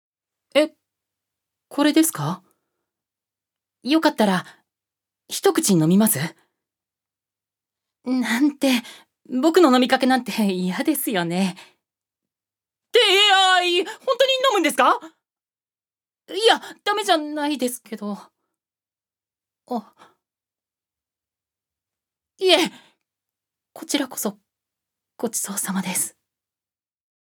預かり：男性
セリフ１